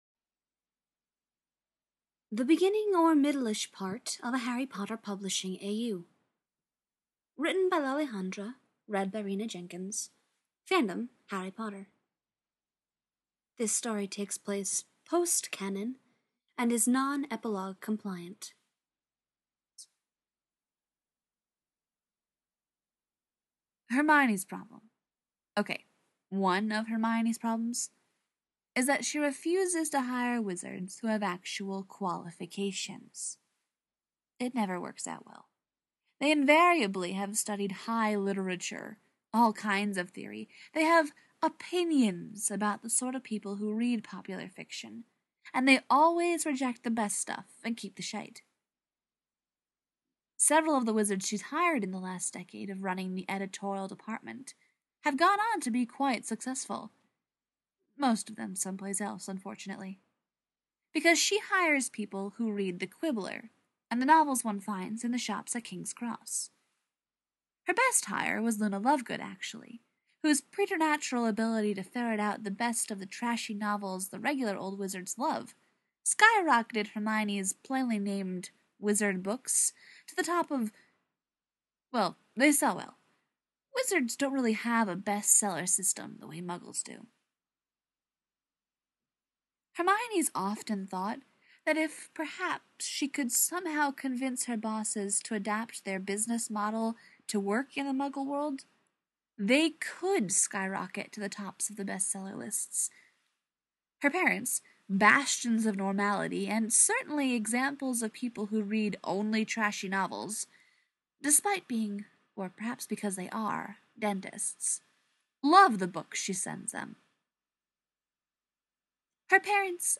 relationship: gabe saporta/pete wentz info: collaboration|two voices